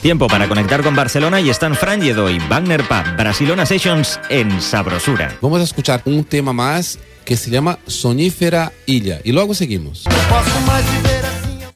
Llatina
Musical